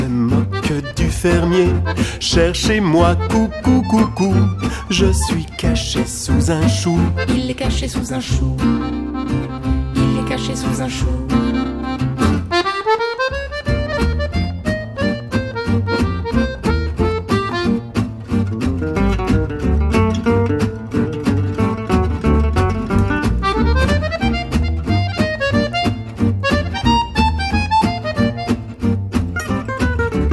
cinq musiciens de jazz manouche.